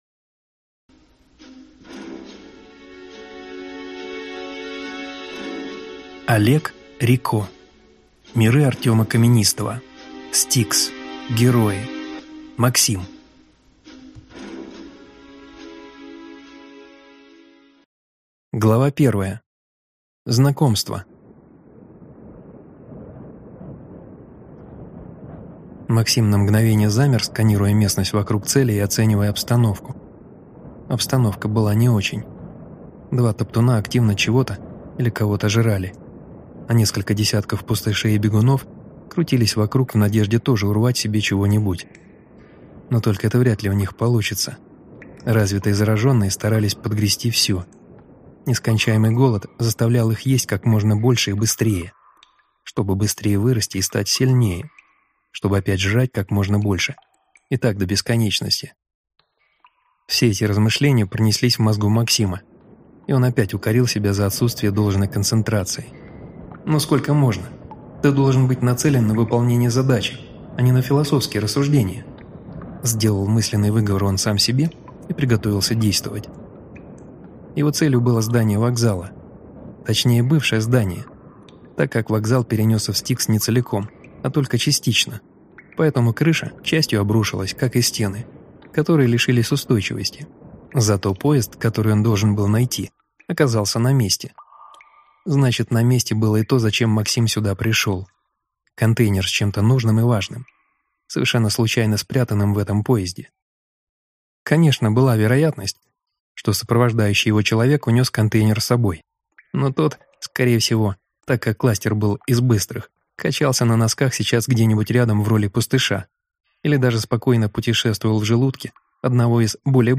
Аудиокнига S-T-I-K-S. Герои. Максим | Библиотека аудиокниг